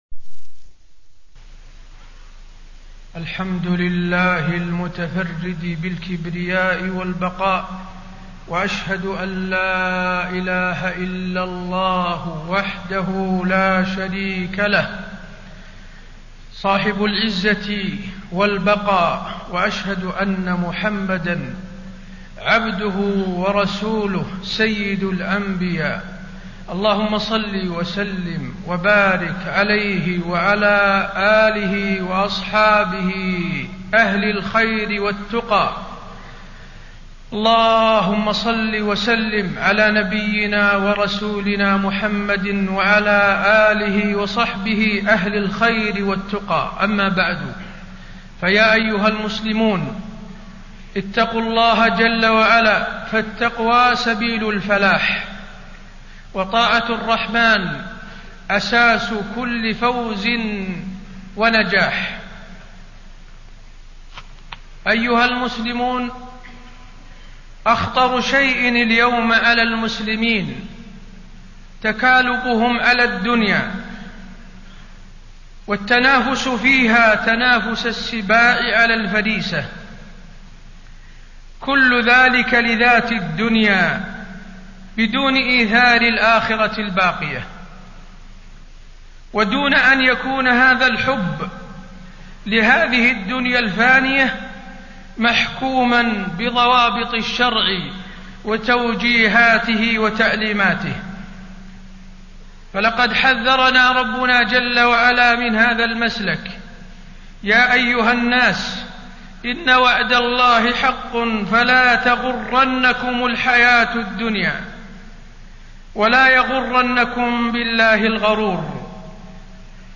تاريخ النشر ٦ جمادى الآخرة ١٤٣٣ هـ المكان: المسجد النبوي الشيخ: فضيلة الشيخ د. حسين بن عبدالعزيز آل الشيخ فضيلة الشيخ د. حسين بن عبدالعزيز آل الشيخ خطورة التكالب على الدنيا